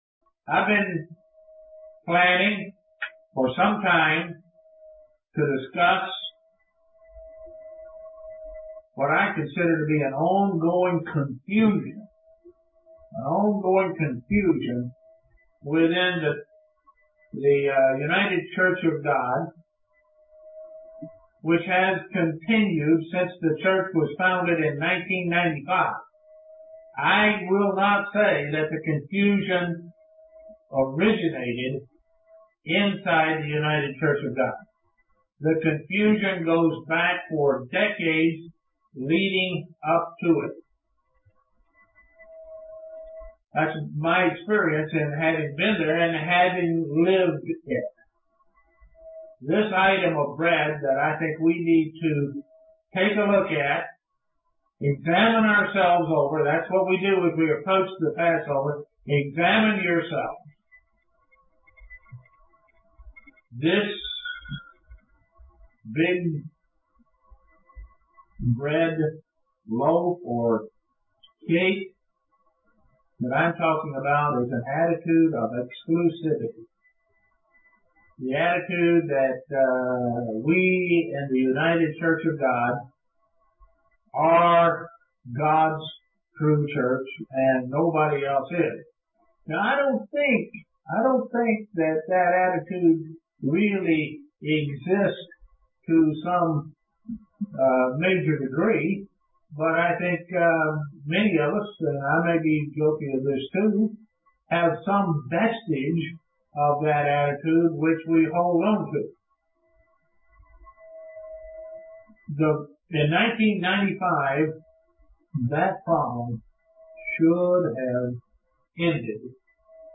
How anyone who truly follows the Bible and what Christ says about this matter is a member of God's Church. (Broadcast from Estonia)